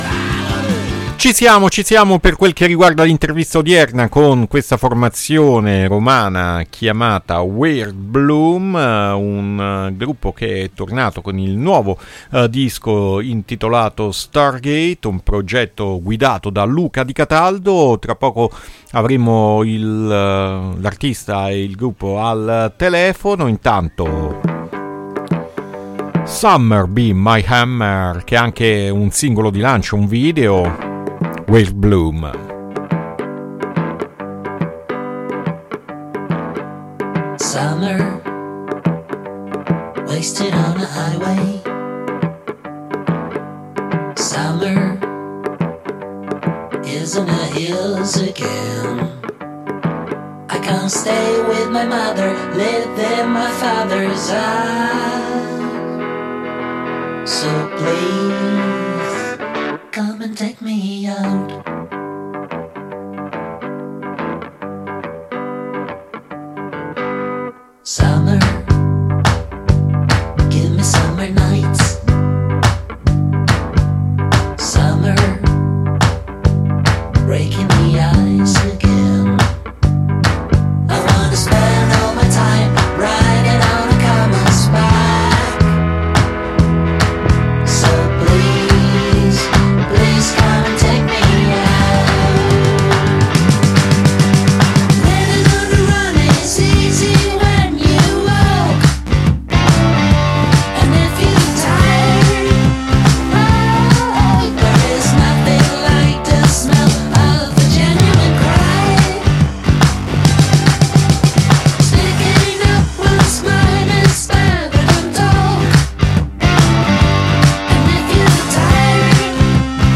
INTERVISTA WEIRD BLOOM A MERCOLEDI' MORNING 5-6-2024